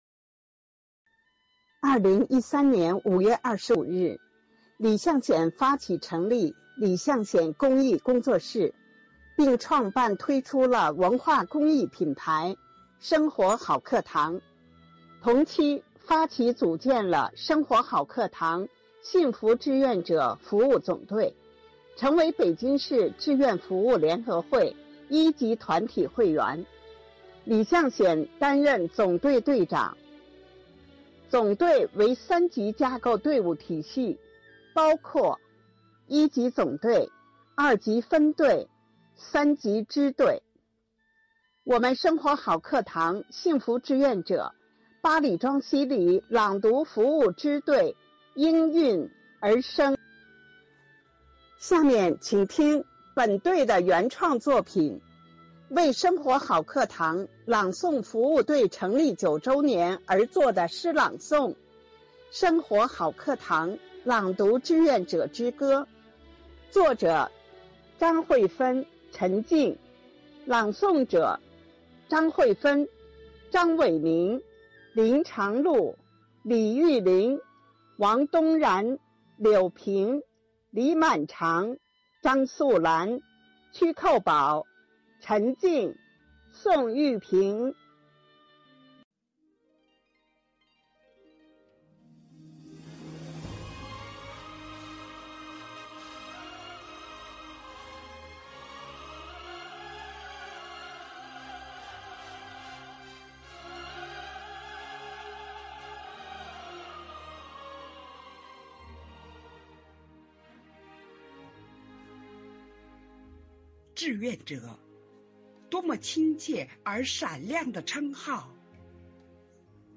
八里庄西里朗读服务队
《生活好课堂志愿者之歌》合诵：八里庄西里朗读支队
《生活好课堂朗读志愿者之歌》合诵：八里庄西里朗读支队.mp3